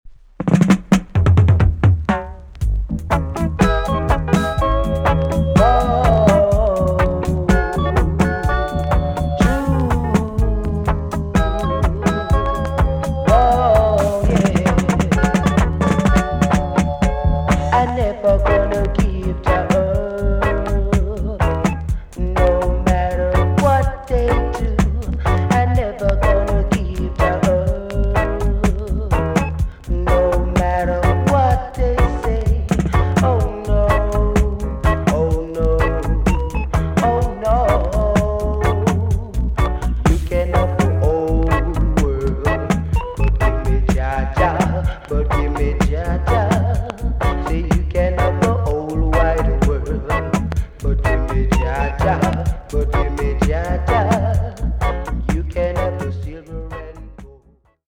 B.SIDE EX- 音はキレイです。